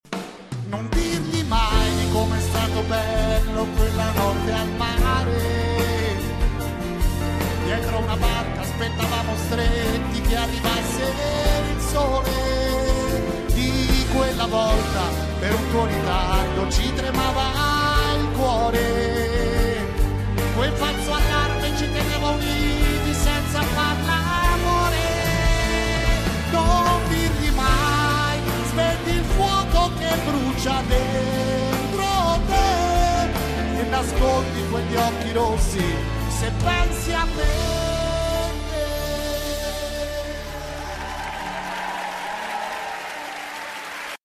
Interpretazione intensa e sentita del cantante
Esibizione del 2019 avvenuta durante lo show